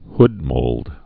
(hdmōld)